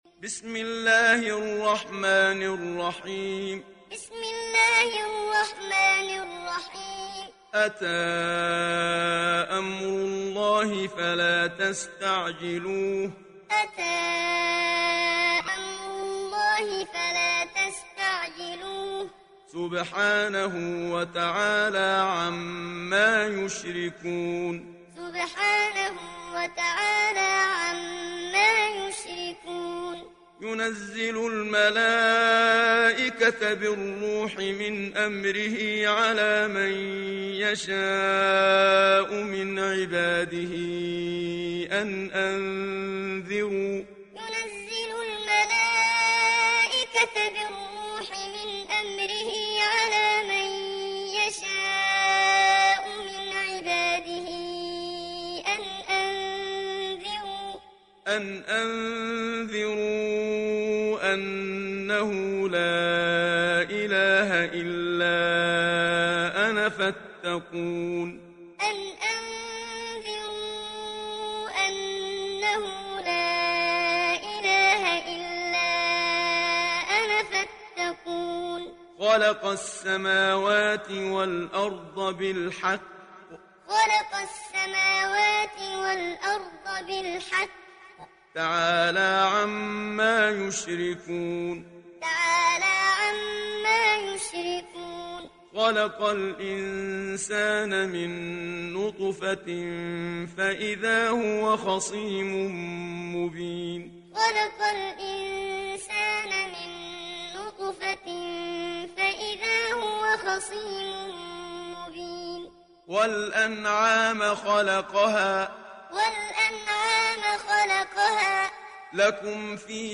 İndir Nahl Suresi Muhammad Siddiq Minshawi Muallim